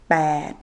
Cliquez ici pour écouter la prononciation de ce chiffre
pêtแปด